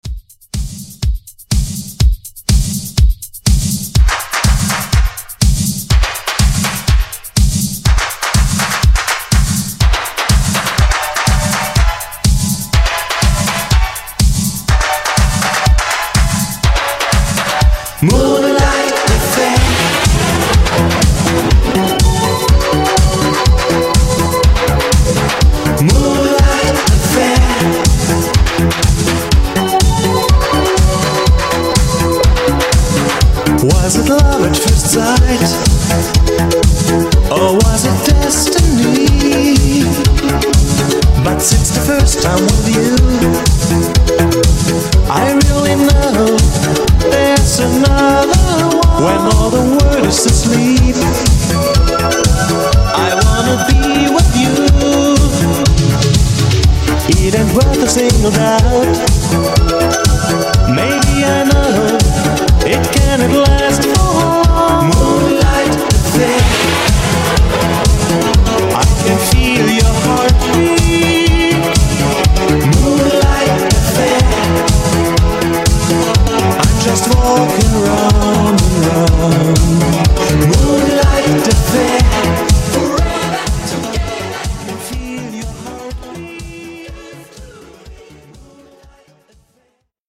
Genre: DANCE
Clean BPM: 105 Time